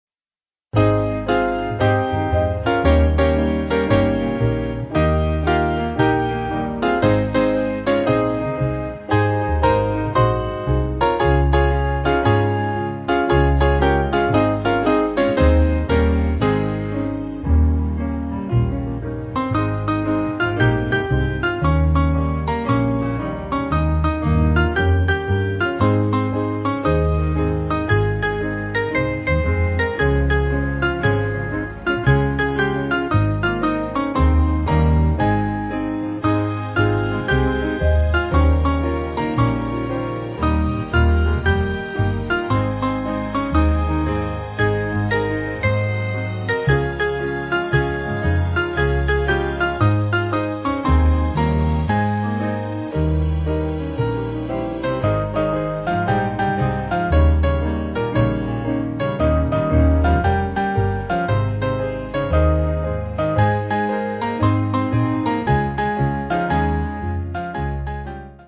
Mainly Piano
Slight lilt